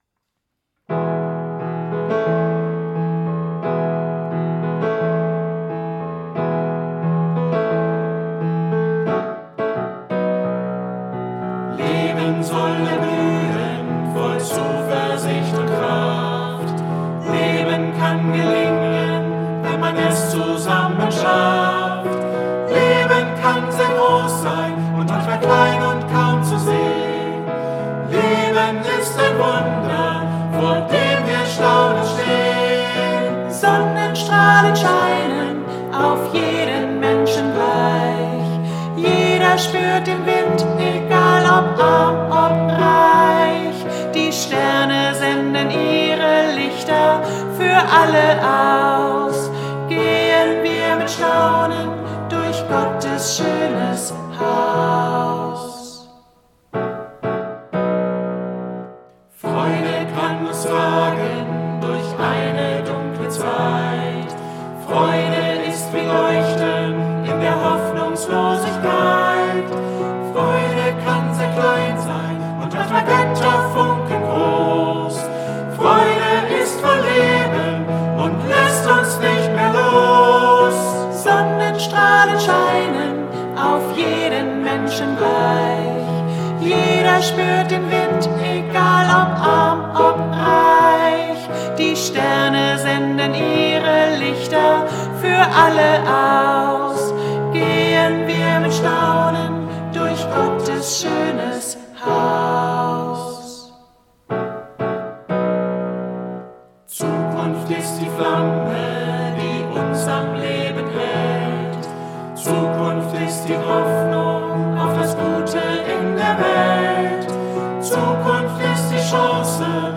Studio-Version